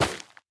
Minecraft Version Minecraft Version 1.21.5 Latest Release | Latest Snapshot 1.21.5 / assets / minecraft / sounds / block / netherwart / break5.ogg Compare With Compare With Latest Release | Latest Snapshot
break5.ogg